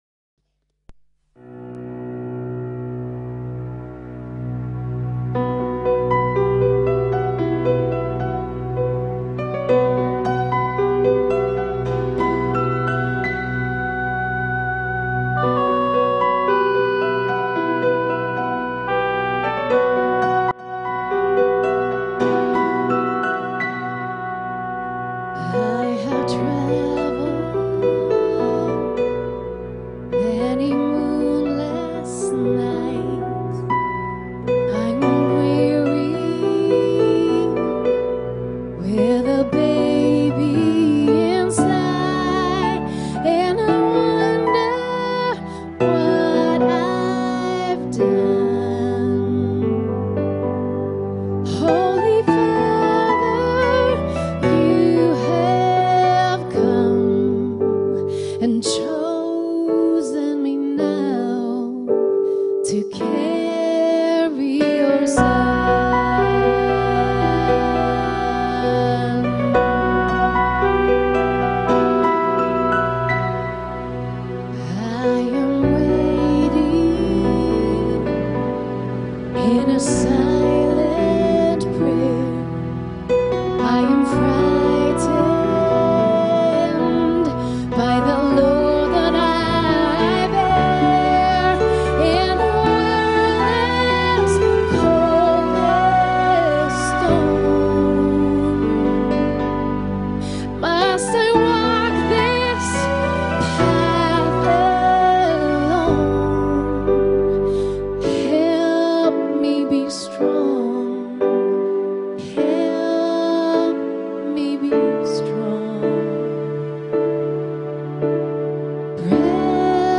WEIHNACHTSPERSPEKTIVE ~ VCC JesusZentrum Gottesdienste (audio) Podcast